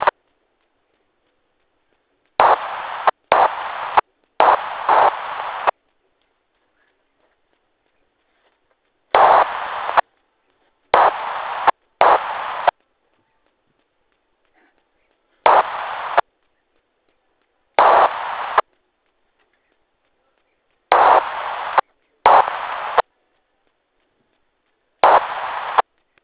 сигнал на опознание